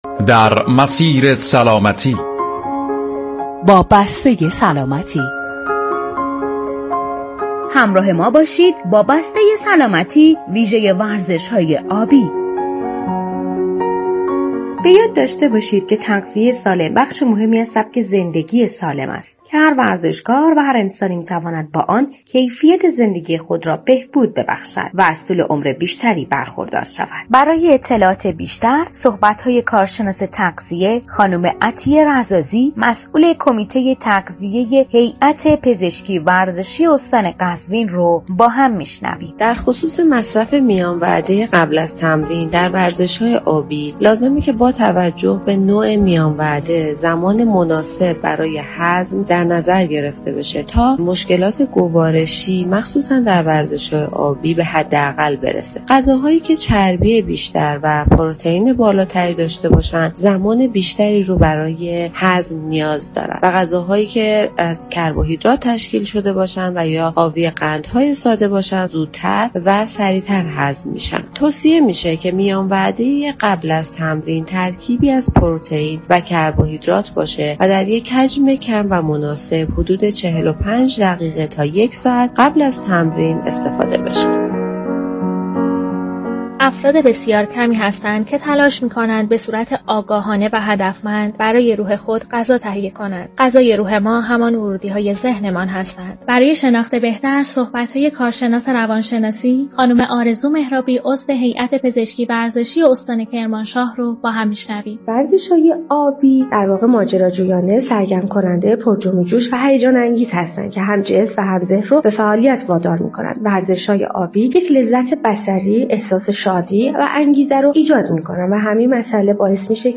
/پزشکی ورزشی و رادیو ورزش/